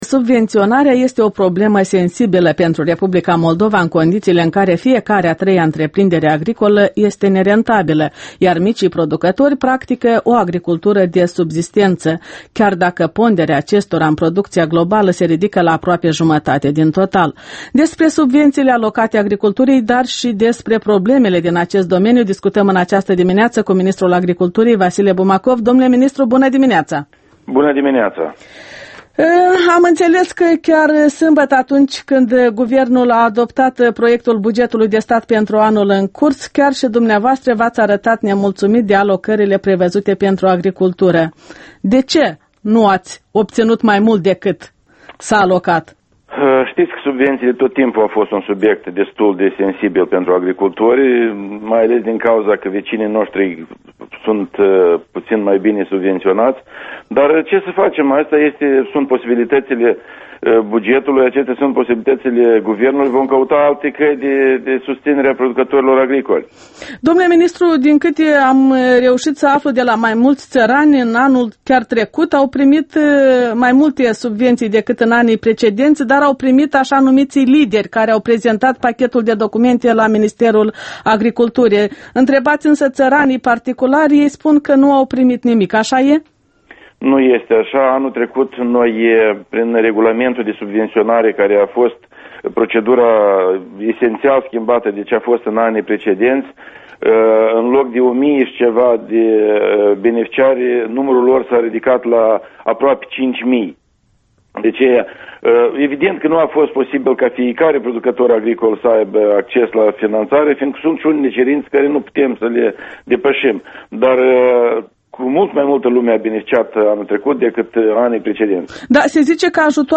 Intrebări și răspunsuri despre situația agriculturii: cu ministrul Vasile Bumacov